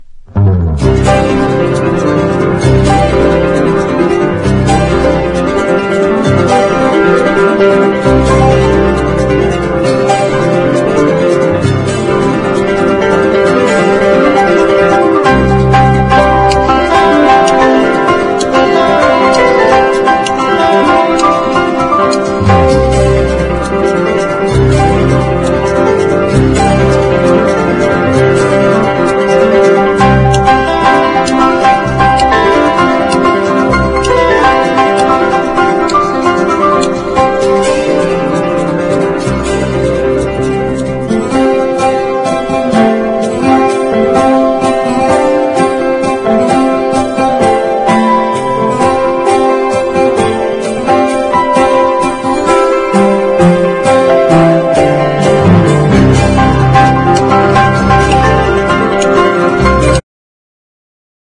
80年の異色シンセ・ディスコ！